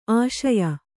♪ āśaya